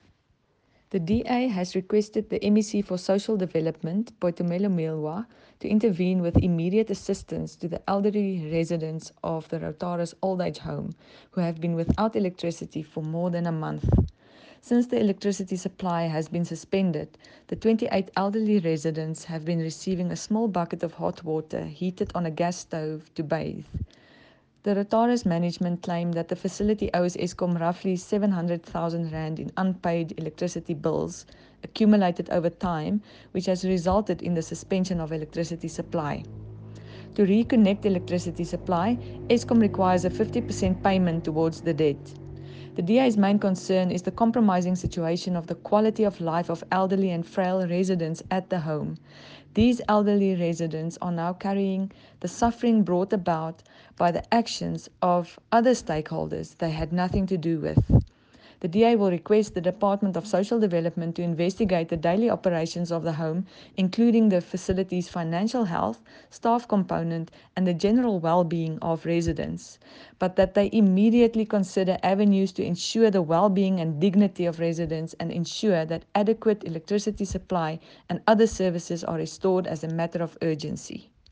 Issued by Cllr Arista Annandale – DA Councillor: Mahikeng Local Municipality
Note to Broadcasters: Please find linked soundbites in